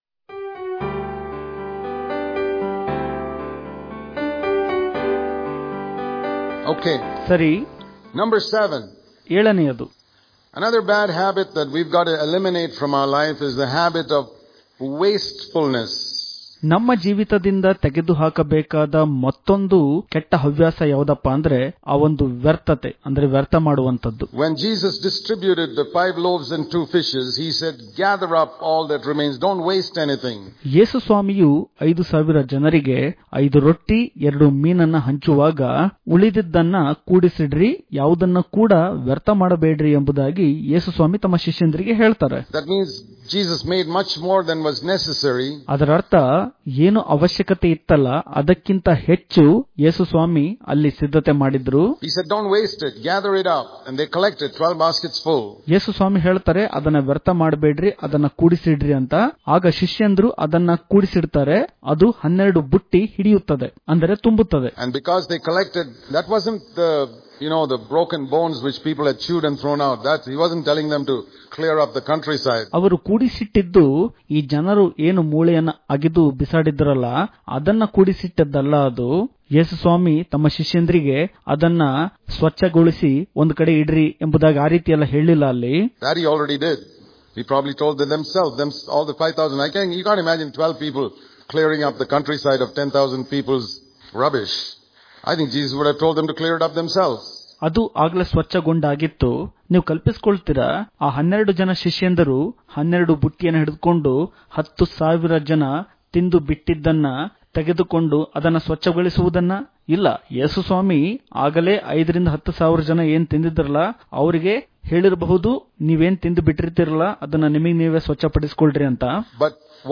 October 8 | Kannada Daily Devotion | Freedom From The Habit Of Wastefulness Daily Devotions